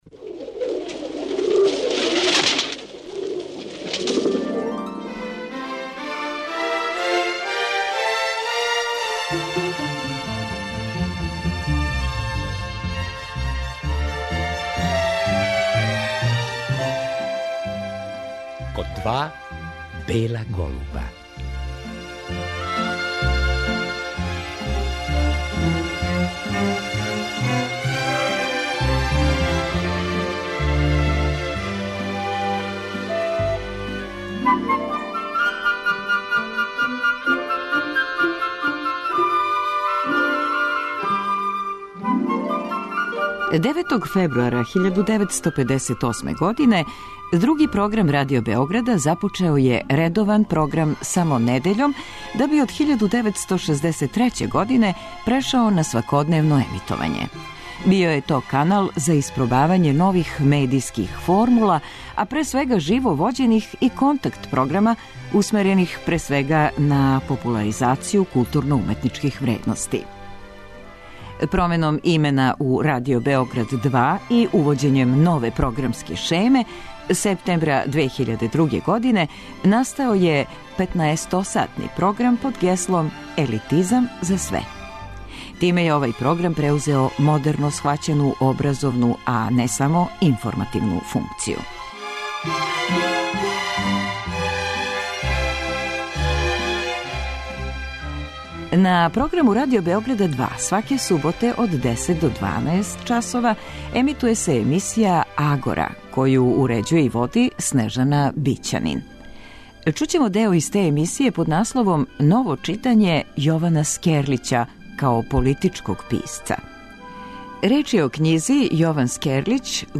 О књизи су говорили историчари